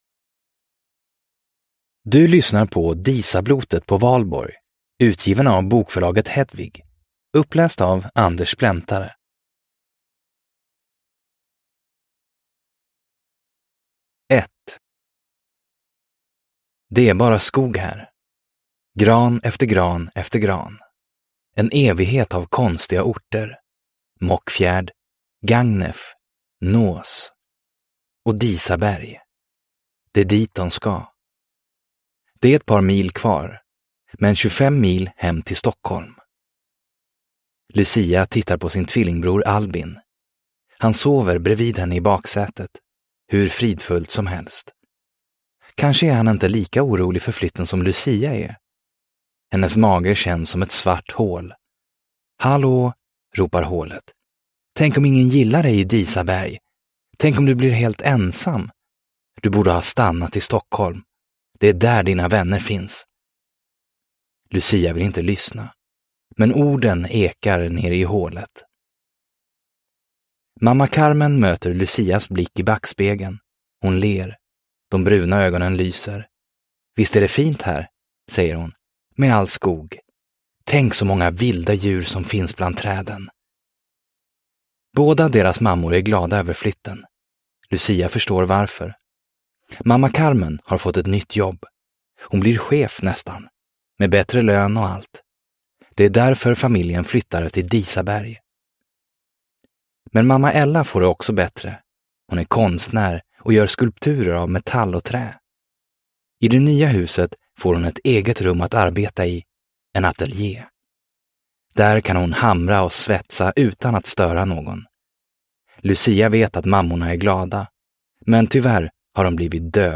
Disablotet på valborg – Ljudbok